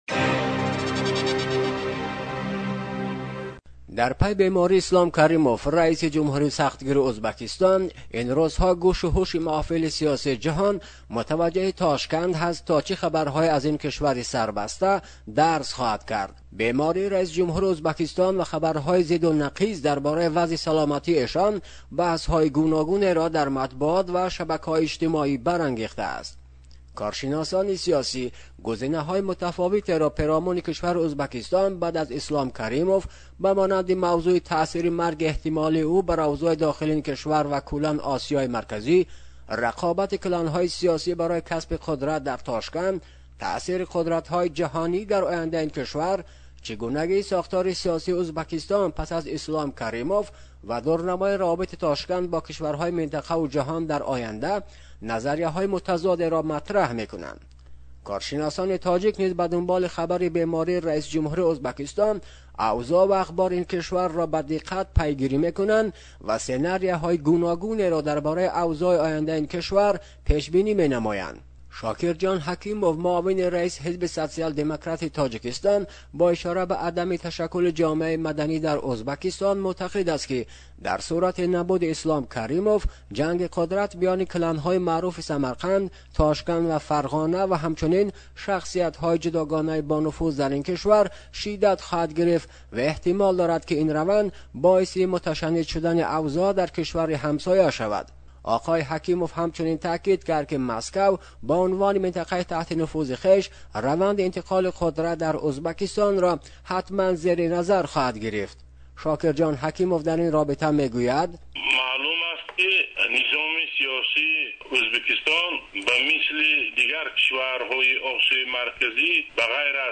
гузориши вижае